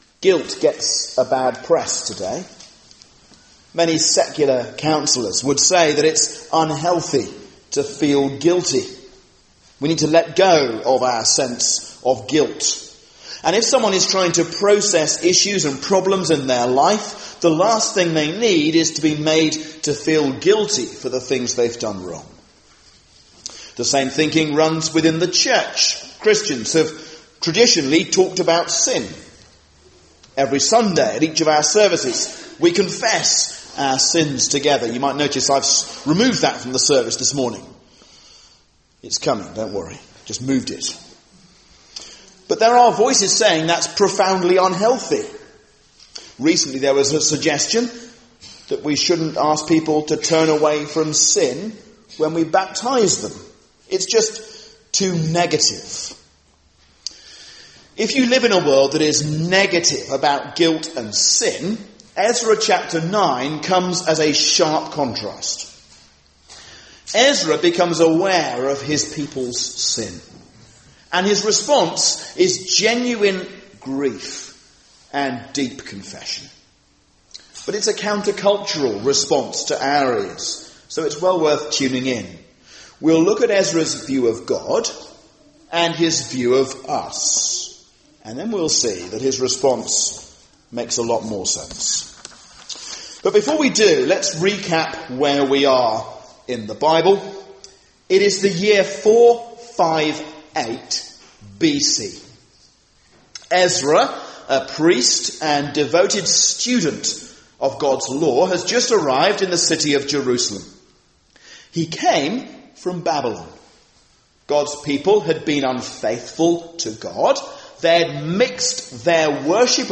A sermon on Ezra 9